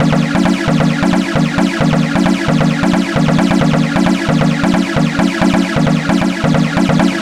Stab 133-BPM 2-C#.wav